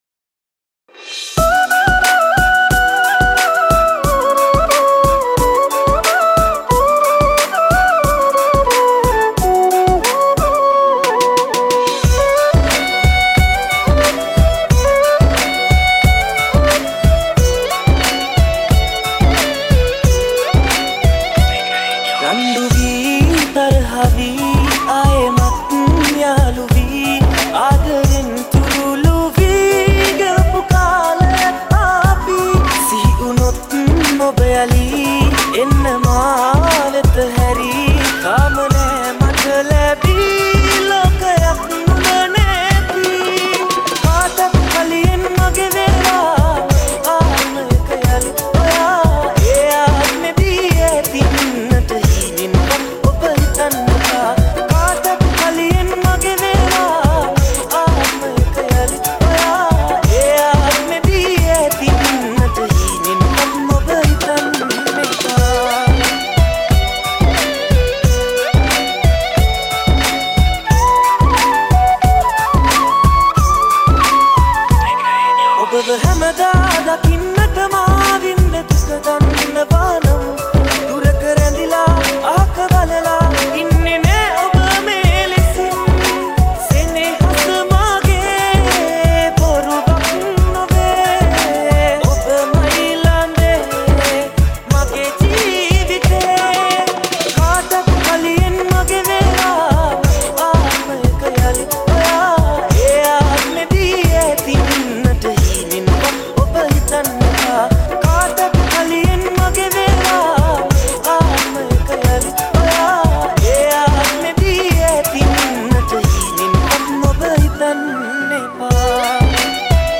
Hip Hop Mix
90 Bpm Mp3 Download